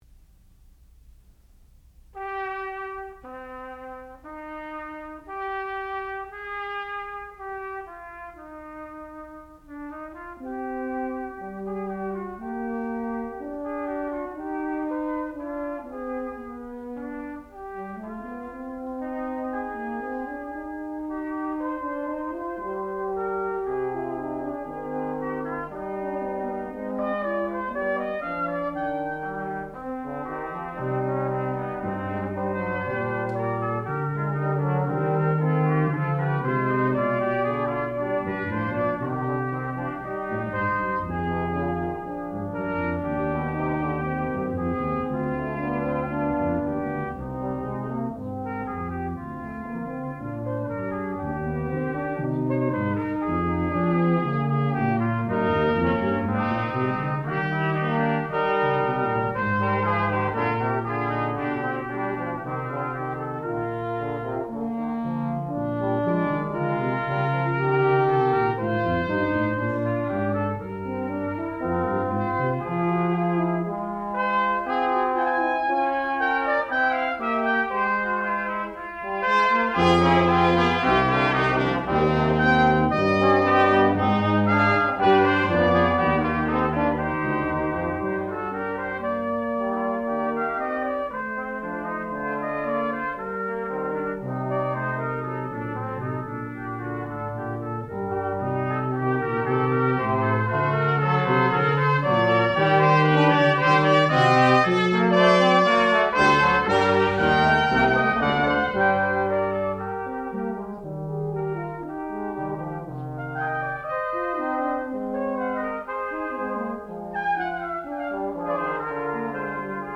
sound recording-musical
classical music
trombone
trumpet
horn